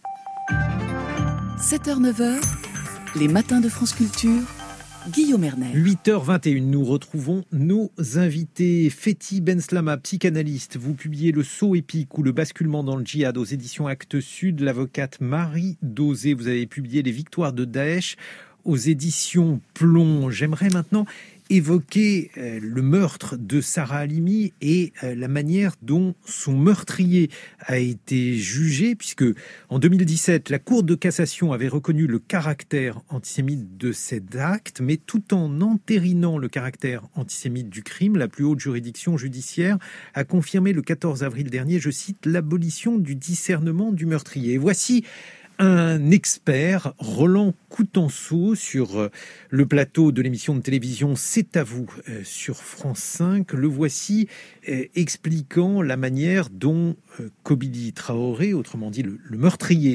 Invitée des matins – Présenté par Guillaume Erner sur France Culture le 29 avril 2021